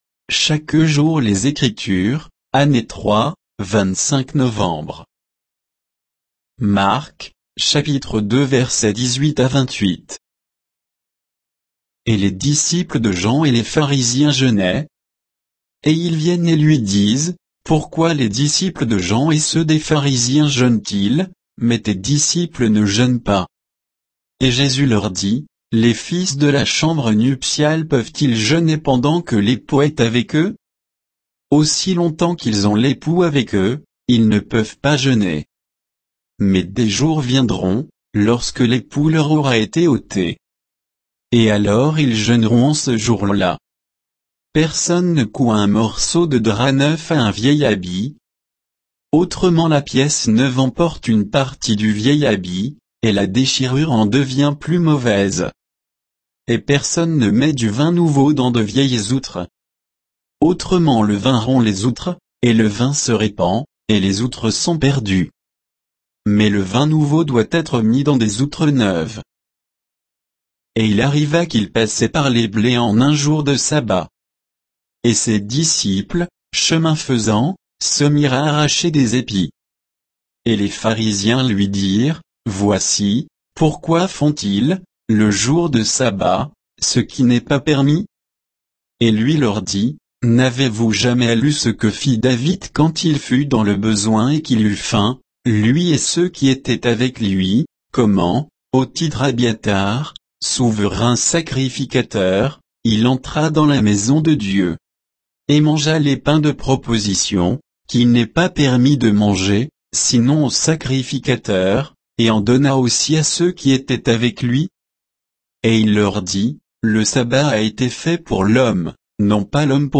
Méditation quoditienne de Chaque jour les Écritures sur Marc 2